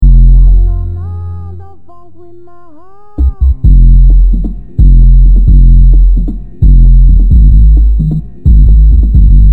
Stop smooching and blasting your car stereo at the same time mutherfoggers!